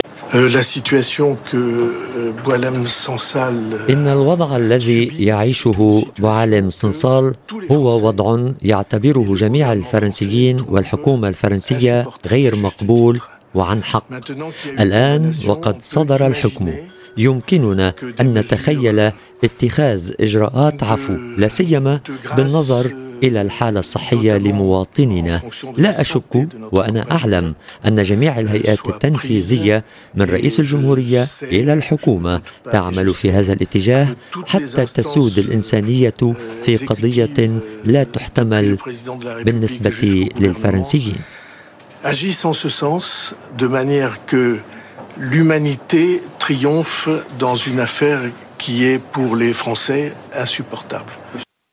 وبعد صدور الحكم عن محكمة الاستئناف، أعرب رئيس الوزراء الفرنسي فرانسوا بايرو عن أسفه العميق لما وصفه بـ”الوضع غير المقبول” الذي يعيشه الكاتب، وقال: